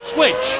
Tags: Murray Walker soundboard F1 formula 1 one commentator motor racing